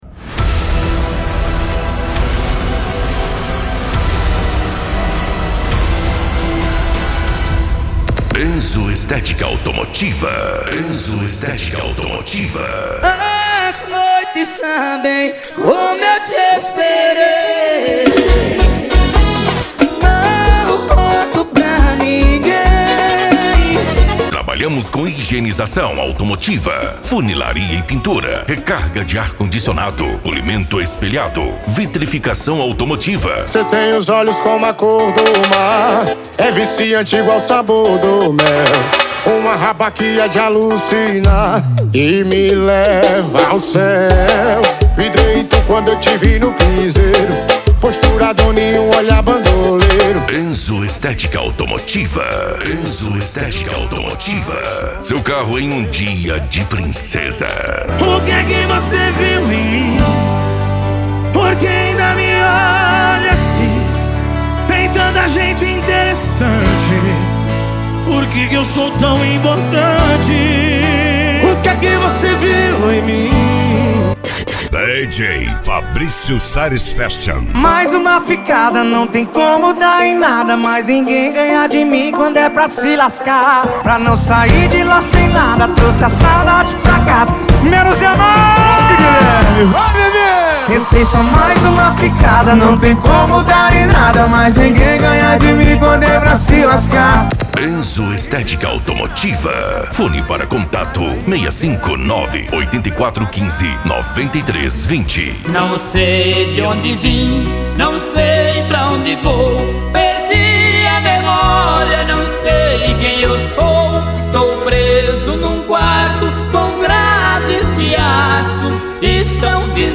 SERTANEJO
Sertanejo Raiz